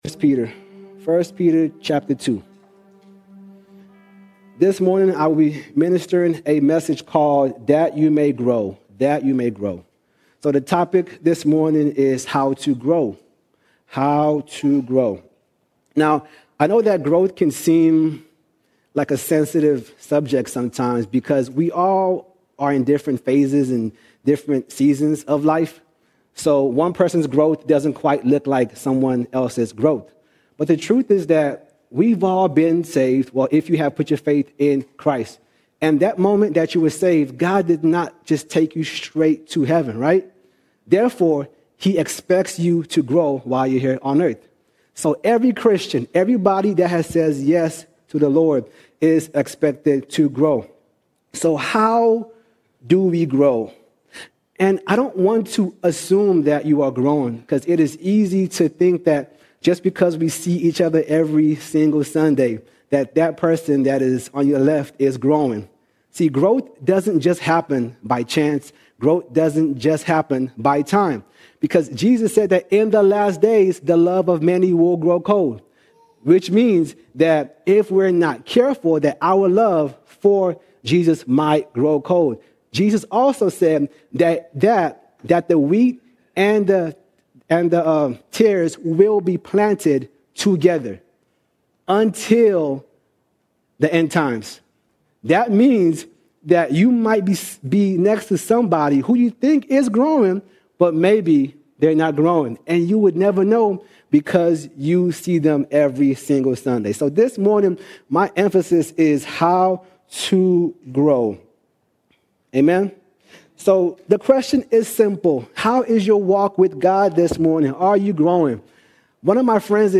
12 April 2026 Series: Sunday Sermons All Sermons That You May Grow That You May Grow How do you actually grow in God?